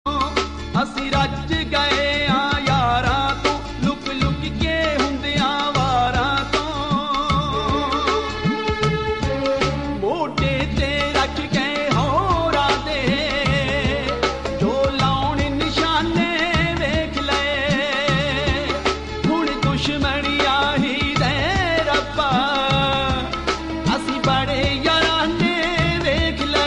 The Whistle